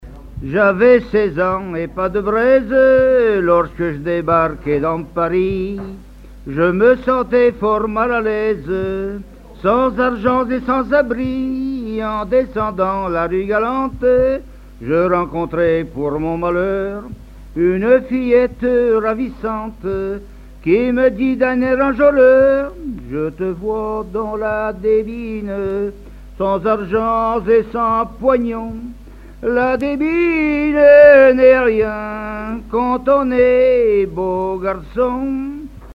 Genre strophique
à la salle d'Orouët
Pièce musicale inédite